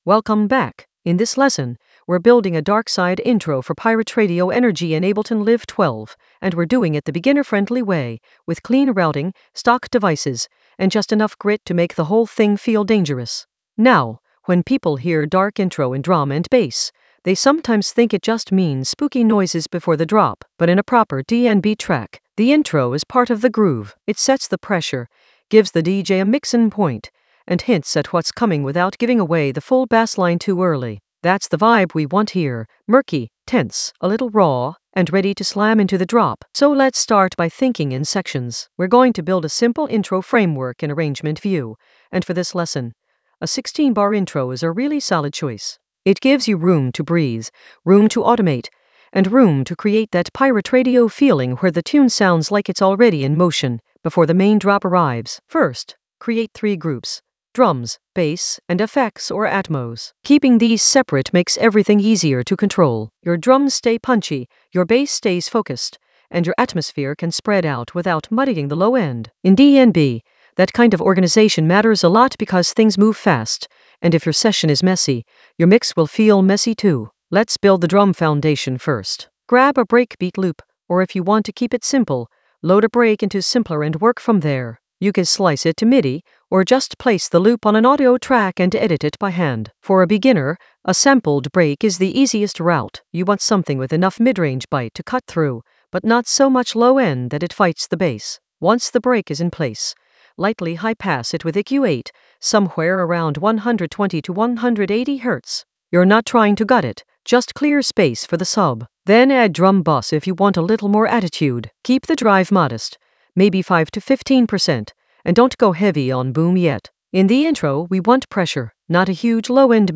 An AI-generated beginner Ableton lesson focused on Route a darkside intro for pirate-radio energy in Ableton Live 12 in the Basslines area of drum and bass production.
Narrated lesson audio
The voice track includes the tutorial plus extra teacher commentary.